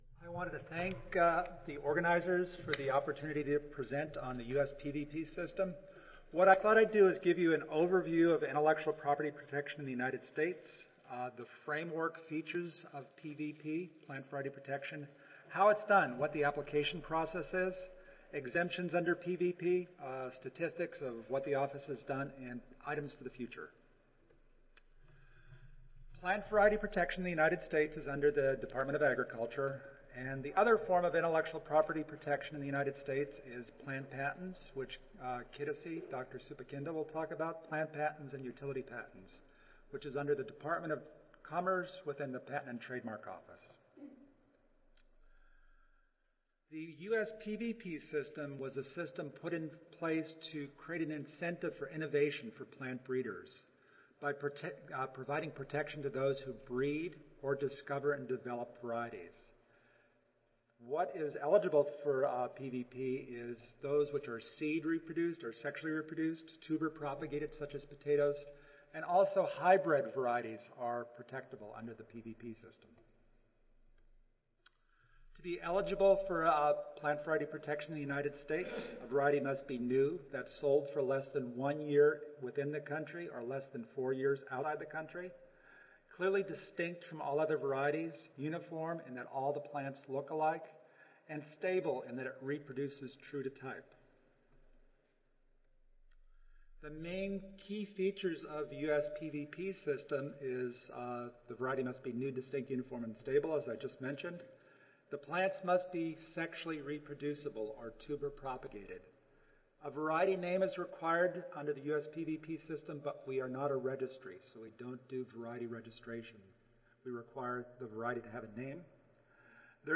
United States Department of Agriculture Audio File Recorded Presentation 1:50 PM 400-2 Patent Protection for Plants